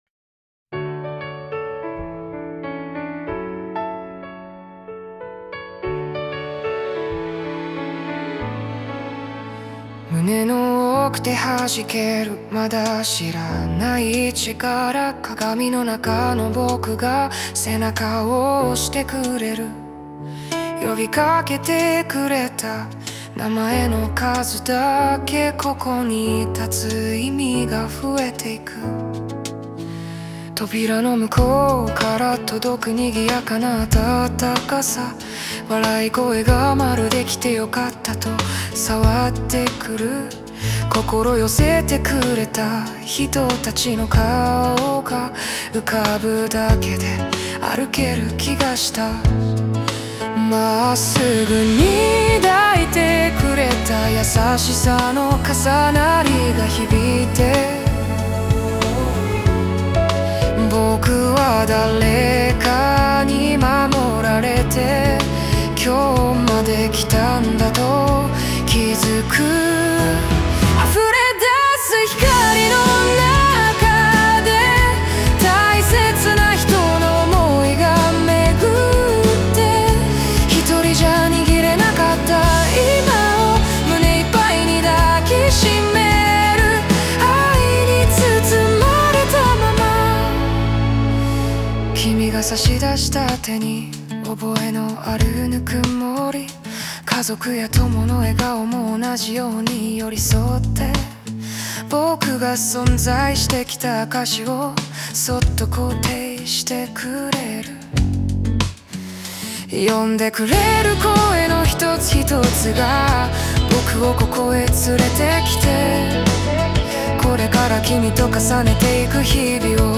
著作権フリーオリジナルBGMです。
男性ボーカル（邦楽・日本語）曲です。
ちょっと中性的な声ですが、これはこれですごく気に入ってます♪♪